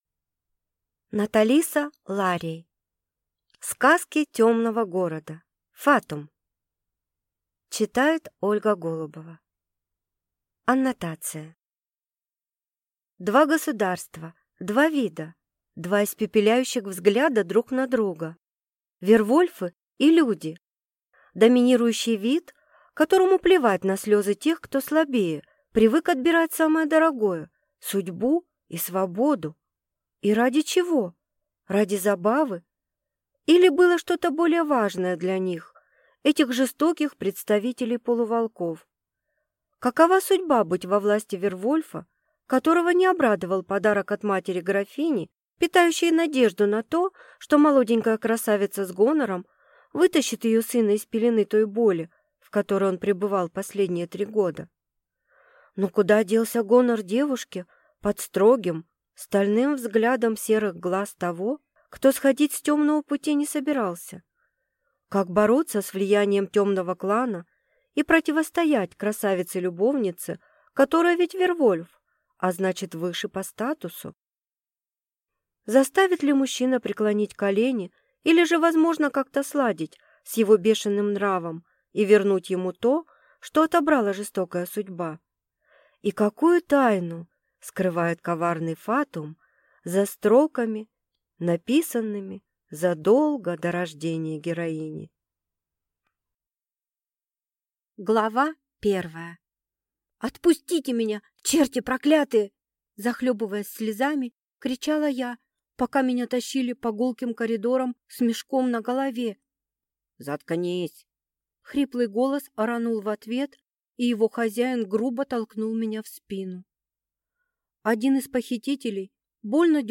Аудиокнига Сказки темного города. Фатум | Библиотека аудиокниг